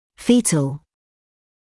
[‘fiːtl][‘фиːтл]эмбриональный, зародышевый (US fetal)